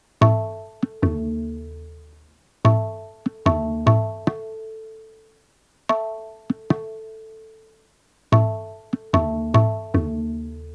Deepchandi_Sample1.wav